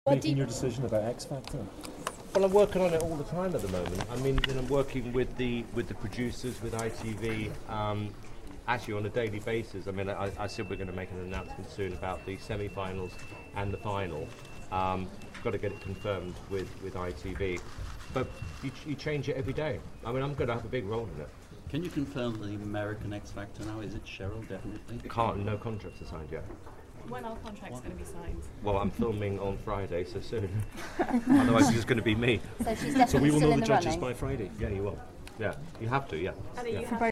Simon Cowell flanked by Ant and Dec at today's press conference
There was plenty of laughter as mega rich Simon, flanked by Ant and Dec, was asked if he bought a Lottery ticket, as you can hear in the short audio clip below: